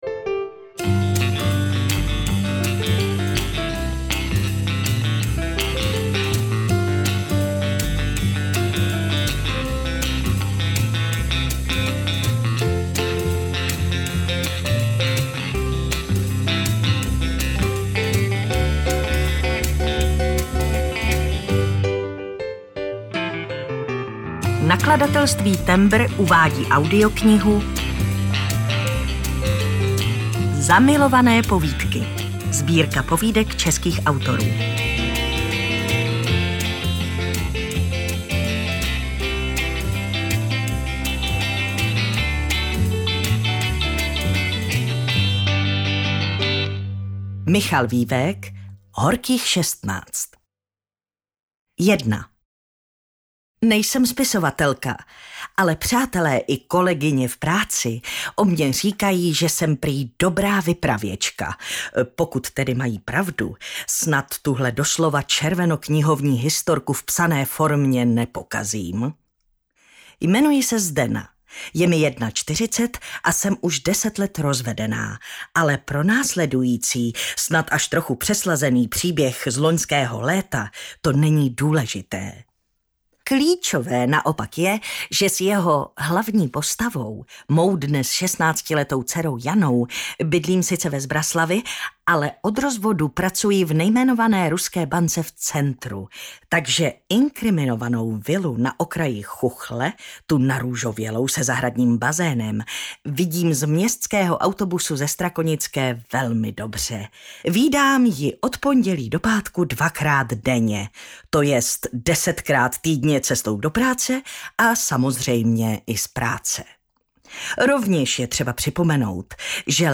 Zamilované povídky audiokniha
Ukázka z knihy
zamilovane-povidky-audiokniha